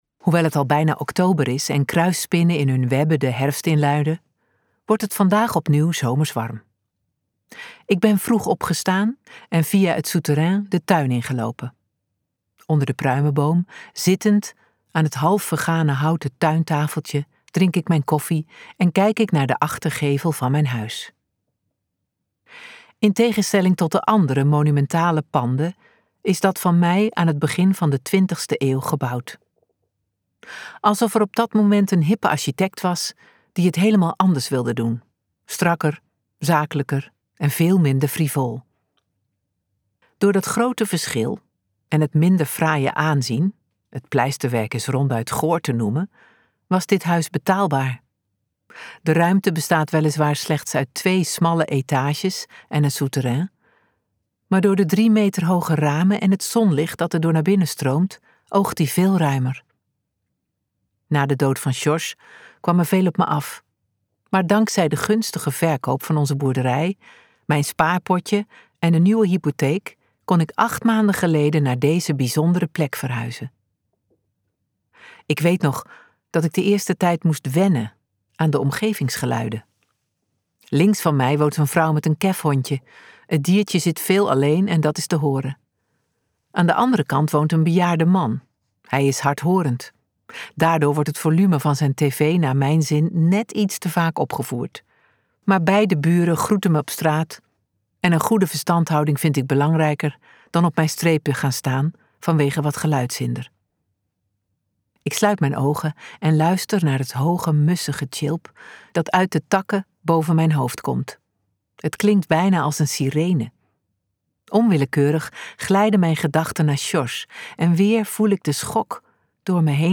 Ambo|Anthos uitgevers - Moedwil luisterboek